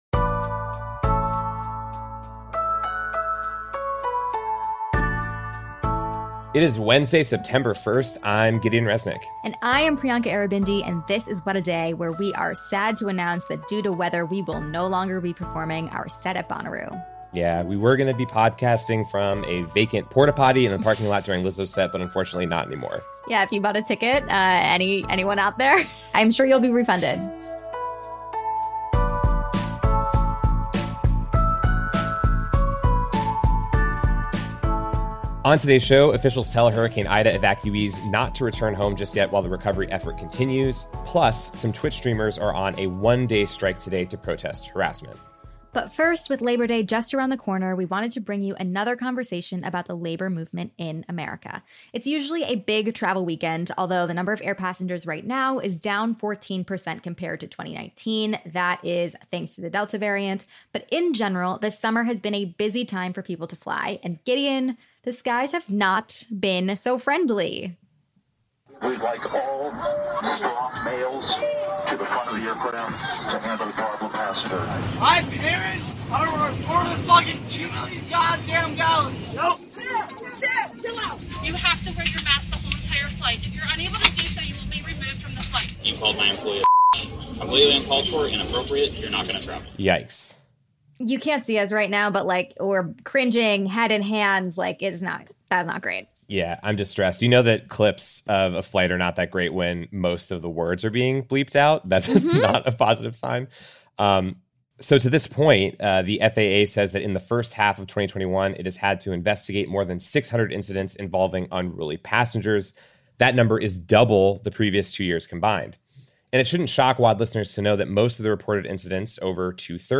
The FAA had to investigate more than 600 incidents involving unruly passengers in the first half of 2021, which is already double the number from the previous two years combined. Sara Nelson, international president of the Association of Flight Attendants, joins us to discuss how flight attendants, as front-line workers, are dealing with these people.